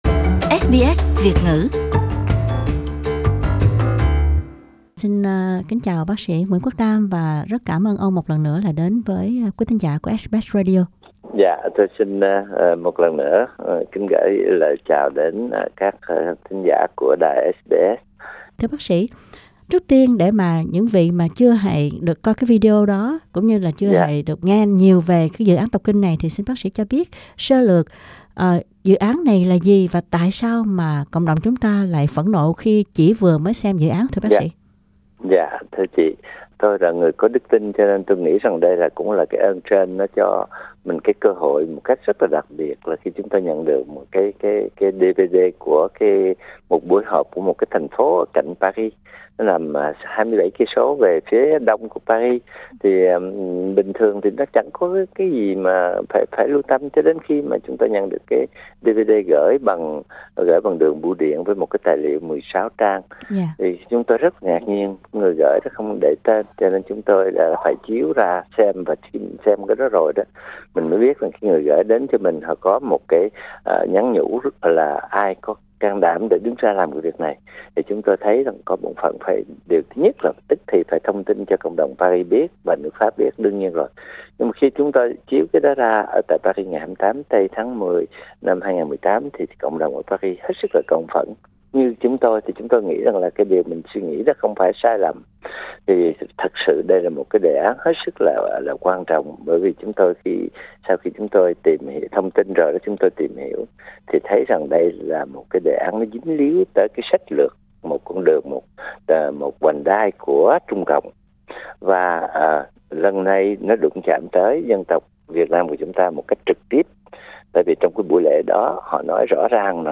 Phần ghi âm phía trên là bài phỏng vấn thứ hai